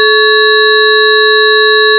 You can listen here to different sound signals composed of the same fundamental frequency (440 Hz) and the same harmonic frequencies (880 Hz, 1320 Hz, 1760 Hz, 2200 Hz) but with different harmonic amplitudes.